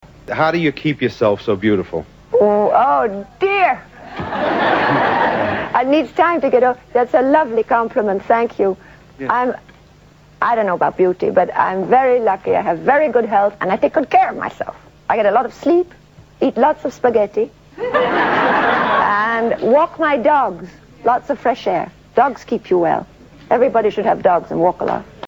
Tags: Audrey Hepburn clips Audrey Hepburn interview Audrey Hepburn audio Audrey Hepburn Actress